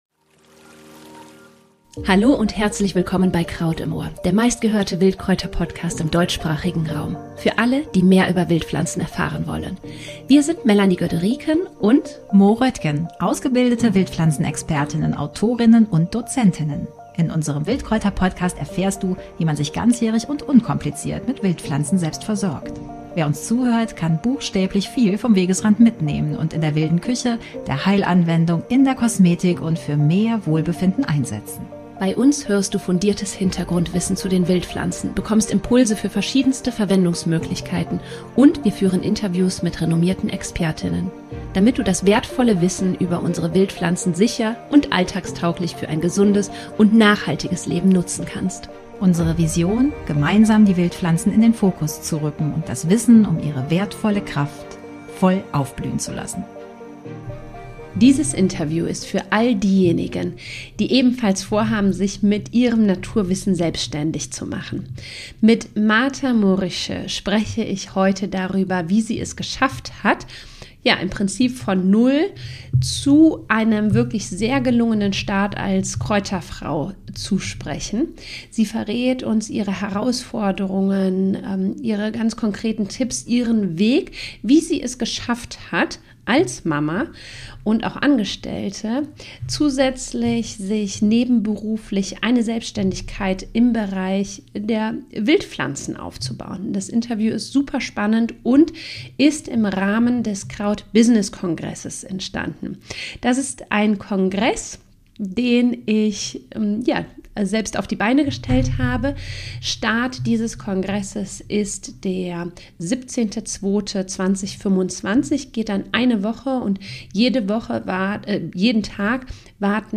Kraut-Interview